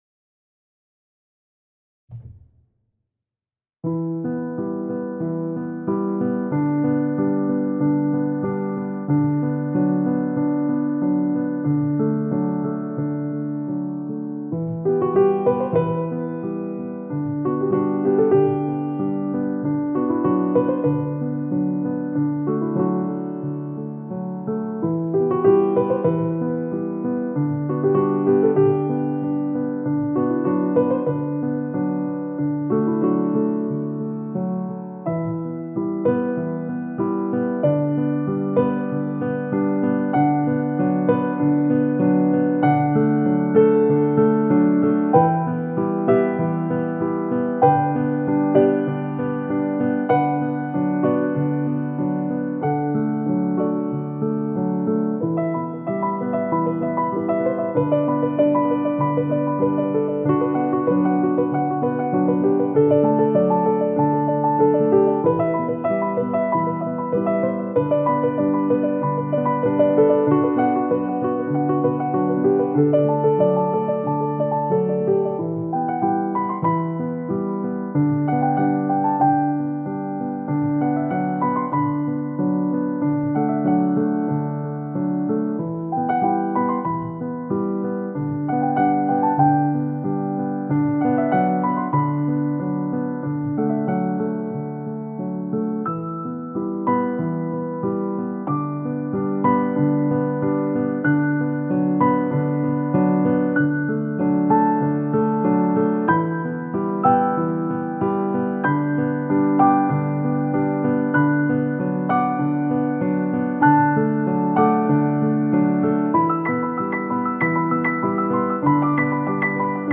BEAUTIFUL INSTRUMENTALS...